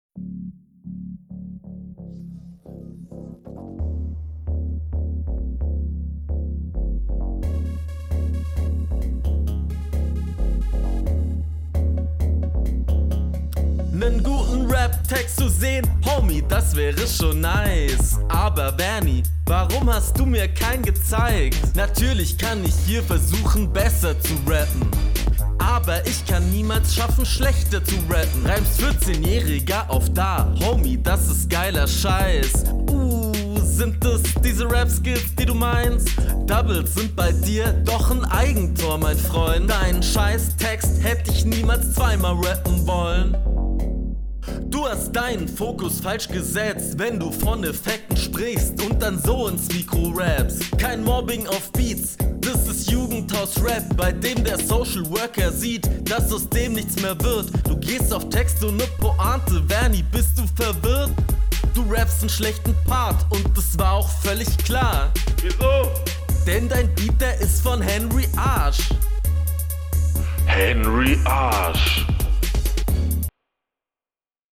Auch hier sehr stark Template geflowt, aber sicherer und besser gemixt.
double-line is nice, flow gefällt mir nicht